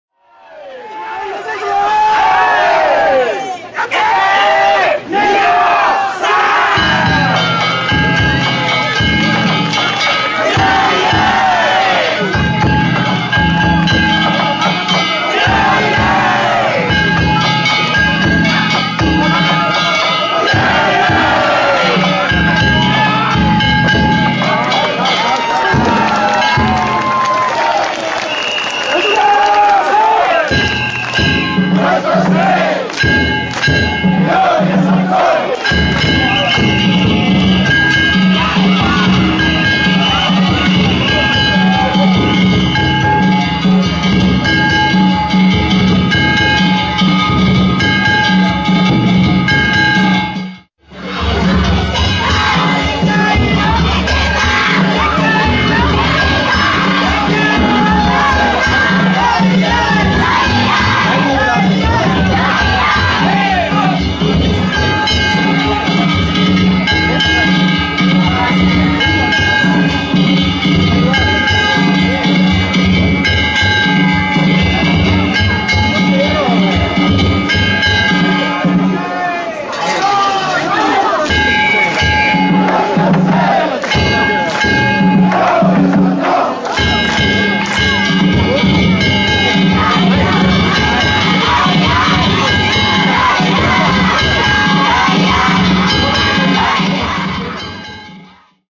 平成２９年７月１６日、生野区の疎開道路パレードを見に行ってきました。
お囃子に合わせて担ぎ上げた地車を揺さぶります。